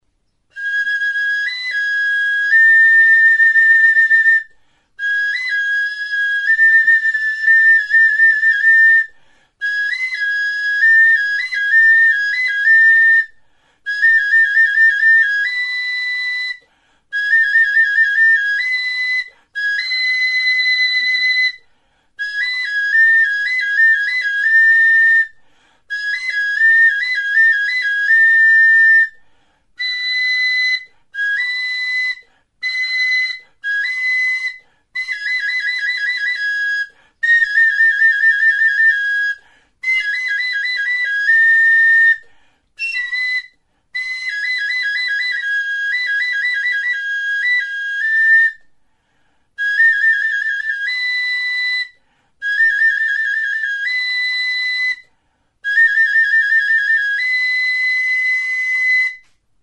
Instrumentos de músicaTXILIBITUA
Aerófonos -> Flautas -> Recta (dos manos) + kena
Grabado con este instrumento.
EUROPA -> EUSKAL HERRIA
Kanaberazko hiru zuloko flauta zuzena da.